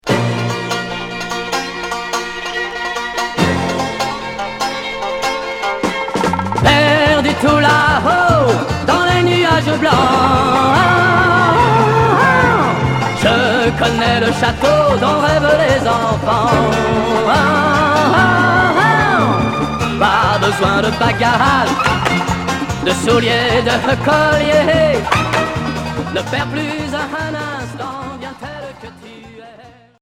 Pop Huitième 45t retour à l'accueil